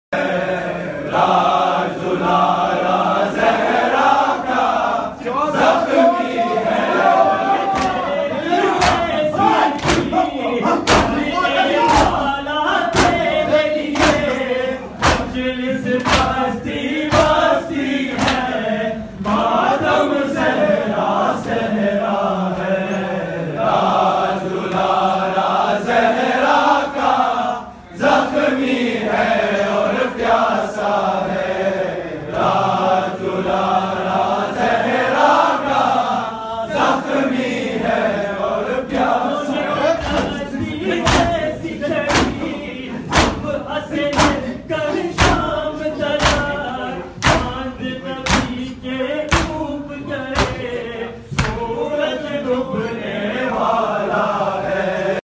Submit lyrics, translations, corrections, or audio for this Nawha.